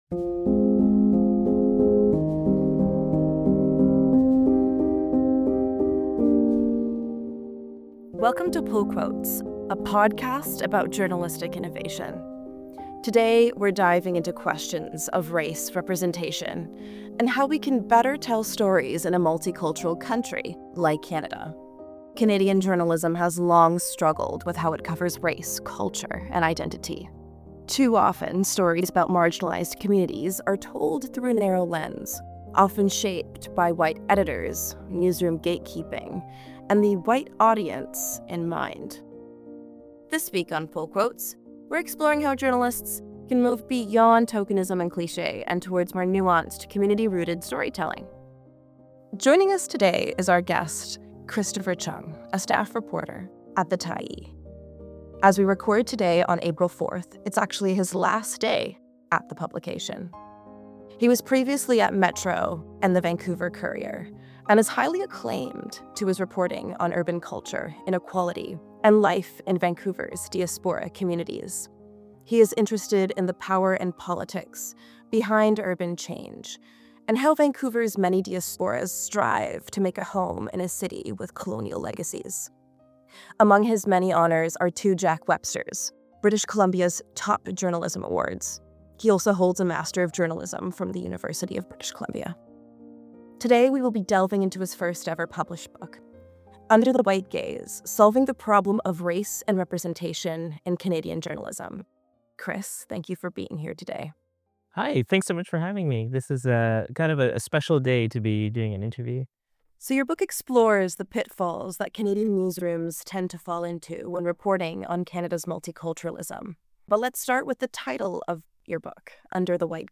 Calm Classical Piano Melody